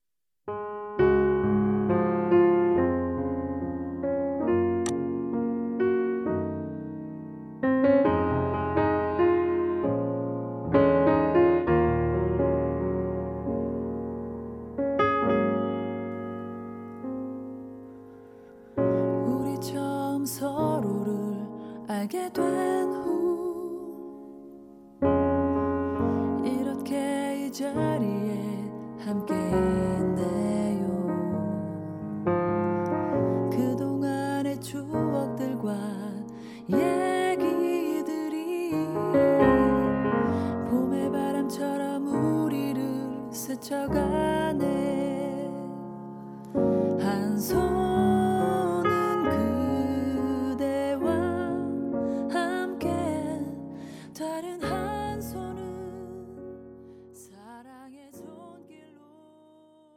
음정 -1키
장르 가요 구분
가사 목소리 10프로 포함된 음원입니다